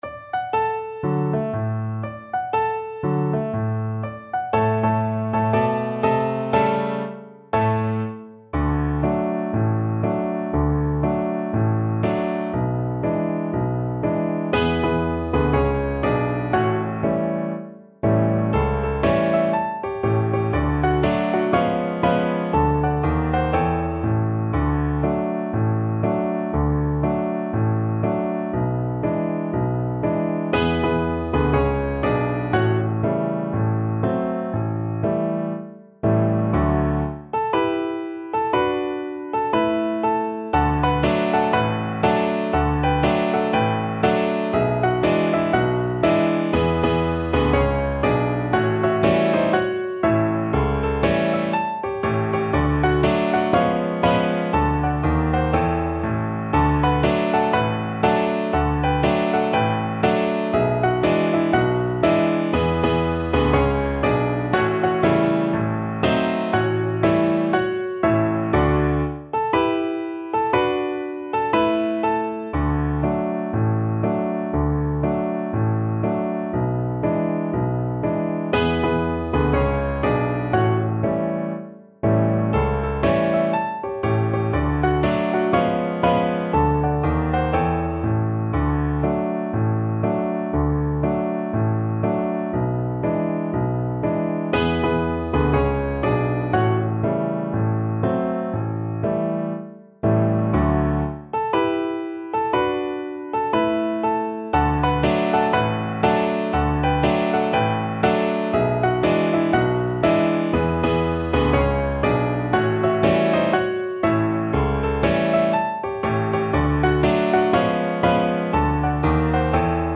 4/4 (View more 4/4 Music)
= 120 Moderato